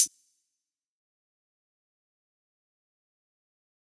OZ - HH 2.wav